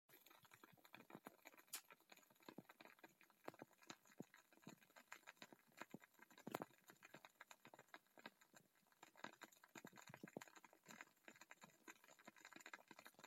Oh ja, het is zo’n knisperende kaars! Heel aangenaam om naar te luisteren en hij ruikt ook superlekker.
hieronder een geluidsfragment van het knisperen: